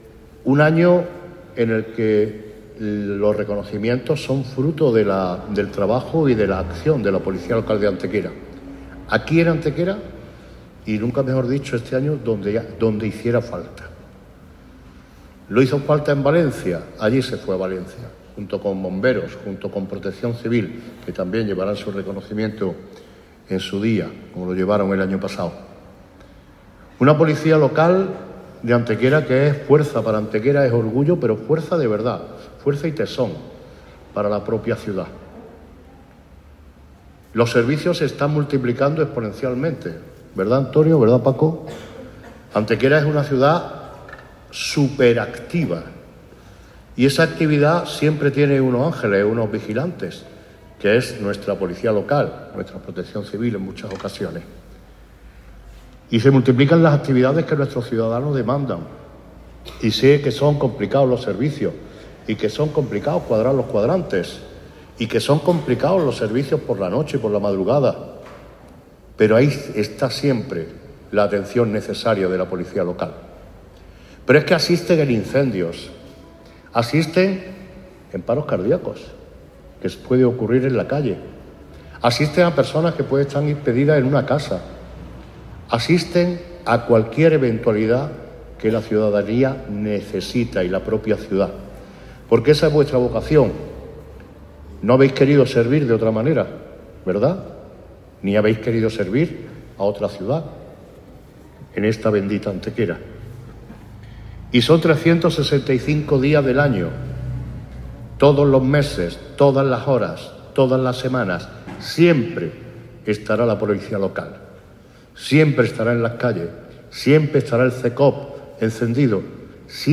El patio del edificio municipal de San Juan de Dios acogió el acto de entrega de reconocimientos al servicio de agentes y civiles de la ciudad, incluyendo este año de forma especial a los que se desplazaron hasta Valencia para colaborar voluntariamente con los damnificados por la DANA.
El alcalde Manolo Barón, que cerró el acto, puso en valor la labor de la Policía Local de Antequera, a la que definió como “fuerza y orgullo para nuestra ciudad, que está siempre presente los 365 días del año, a cualquier hora y en cualquier circunstancia”.
Cortes de voz